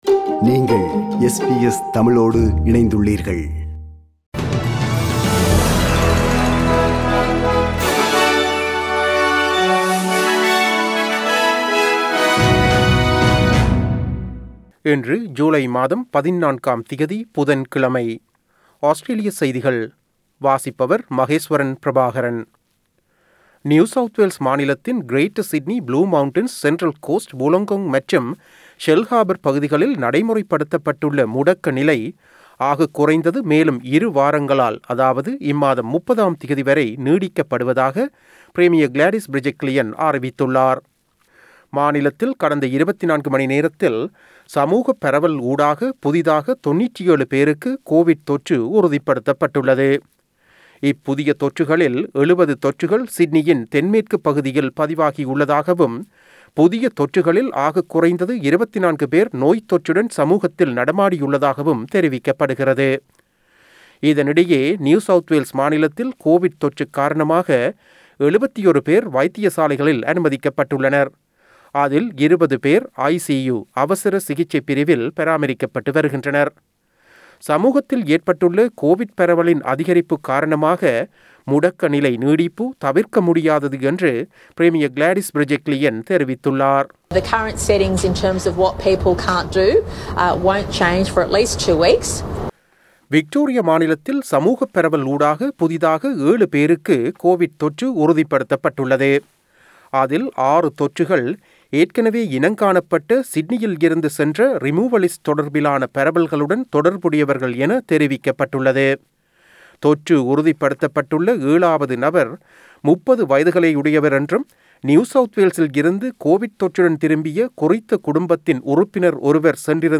Australian news bulletin for Wednesday 14 July 2021.